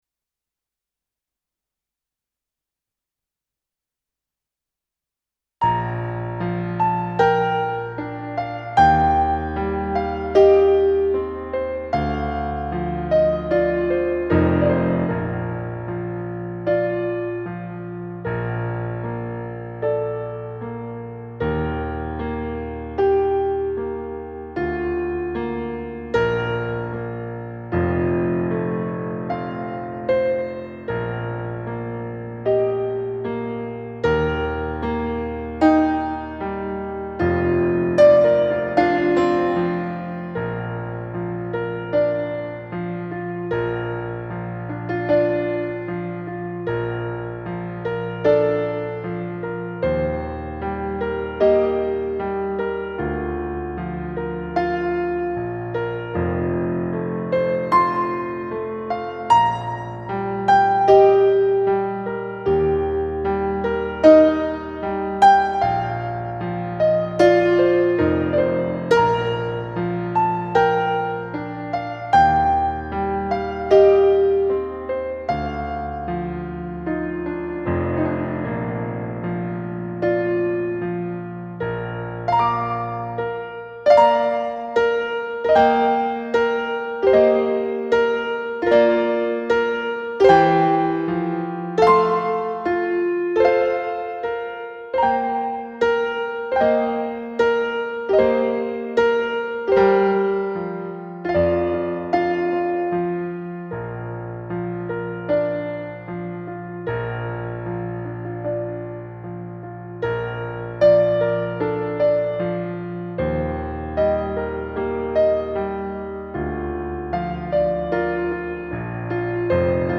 Long Time Ago – Backing | Ipswich Hospital Community Choir
Long-Time-Ago-Backing.mp3